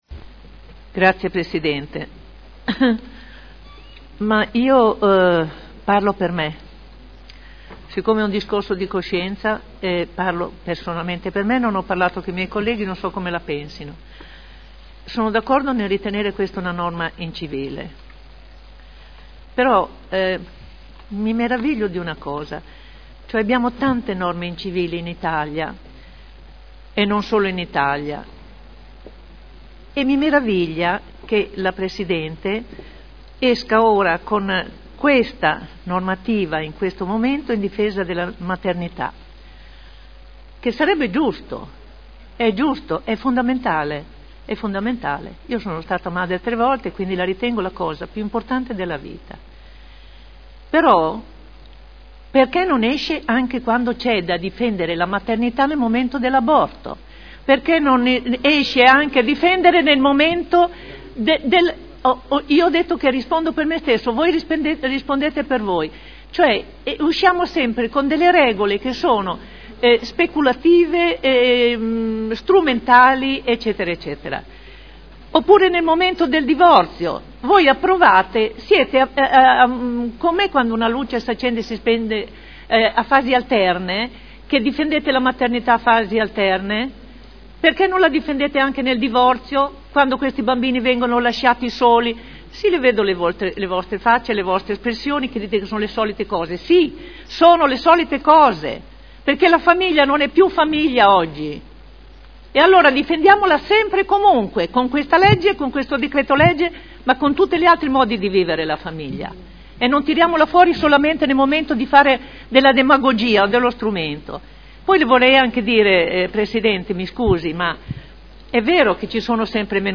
Olga Vecchi — Sito Audio Consiglio Comunale